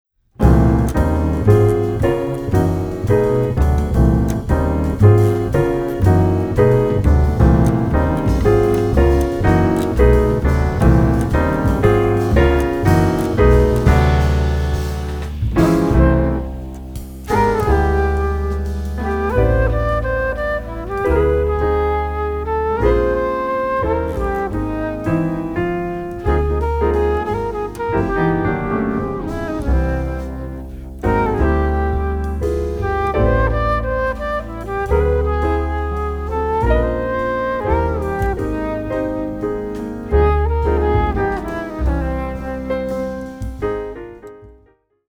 クールで情熱的なオリジナルアレンジがジャズの伝統と現代を融合。
深いグルーヴ、洗練された旋律、躍動感が織りなす極上のサウンド。